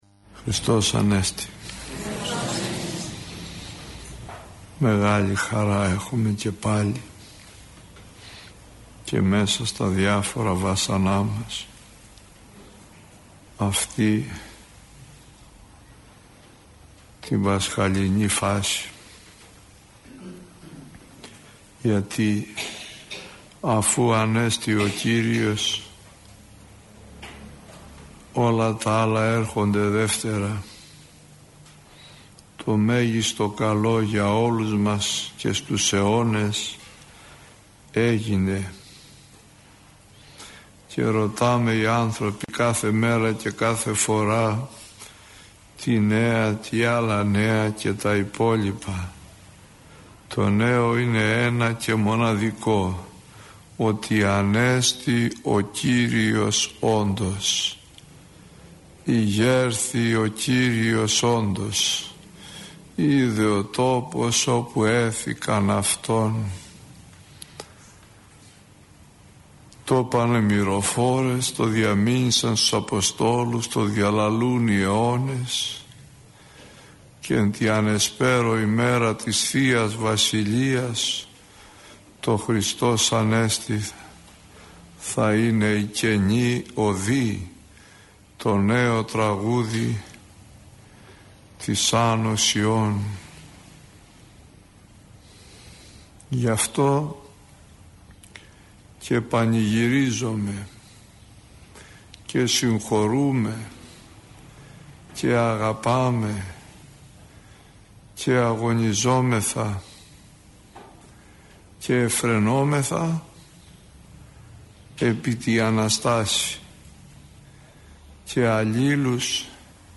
Άγιοι που εορτάζουν από 15 έως 20 Μαϊου – ηχογραφημένη ομιλία